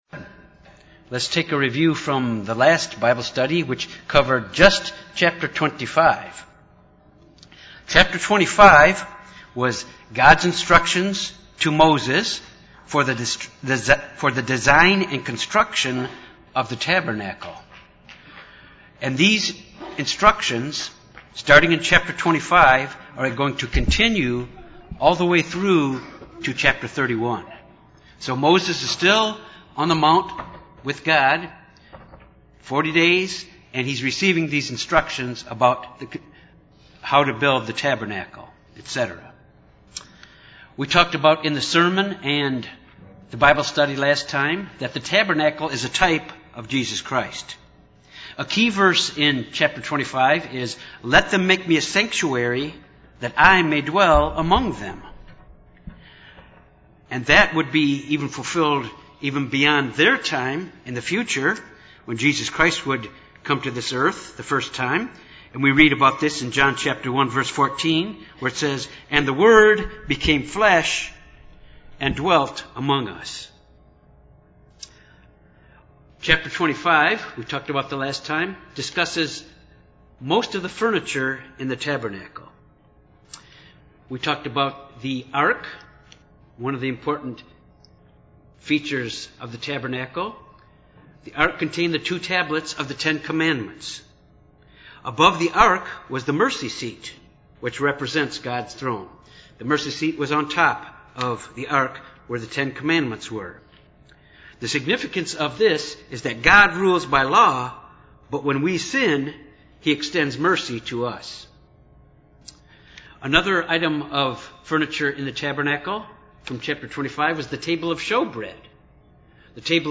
This Bible Study examines the features on the Tabernacles.
Given in Little Rock, AR